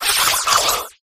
clobbopus_ambient.ogg